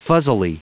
Prononciation du mot fuzzily en anglais (fichier audio)
Prononciation du mot : fuzzily